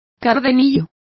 Complete with pronunciation of the translation of verdigris.